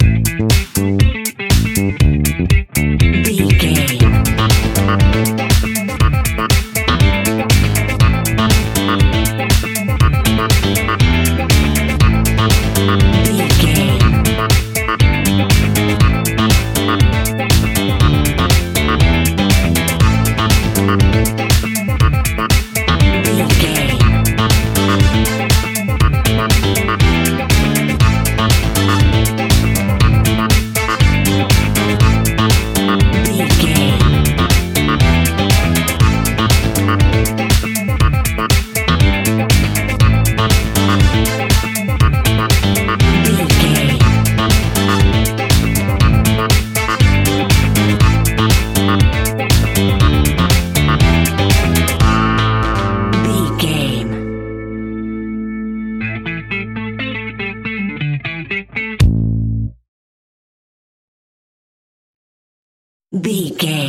Aeolian/Minor
funky
groovy
uplifting
driving
energetic
bass guitar
electric guitar
drums
electric organ
brass
funky house
disco house
electro funk
upbeat
synth leads
Synth Pads
synth bass
drum machines